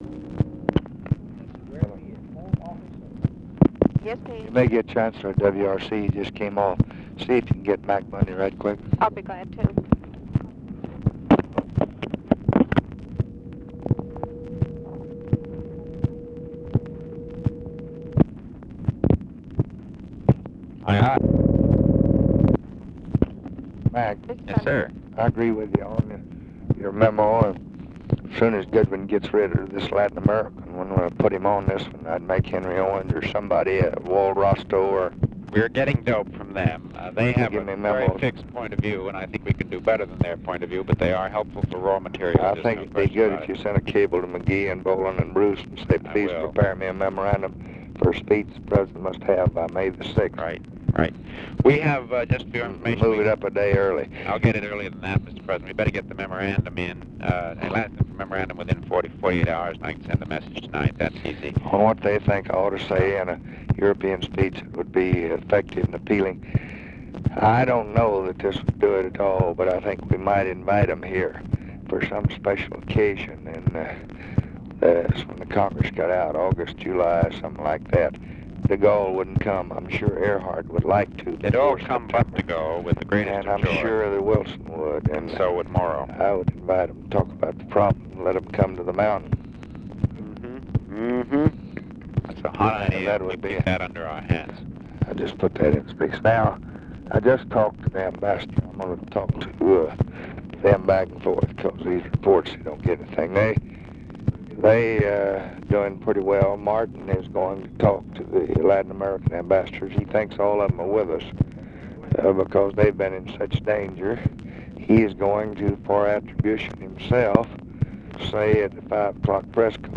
Conversation with MCGEORGE BUNDY and TELEPHONE OPERATOR, May 2, 1965
Secret White House Tapes